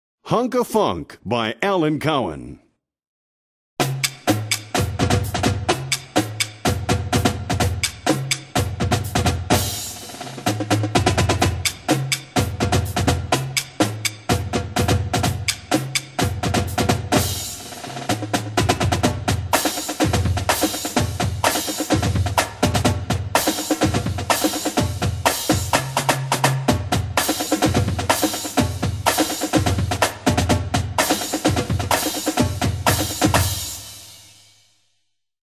Voicing: Percussion Feature Level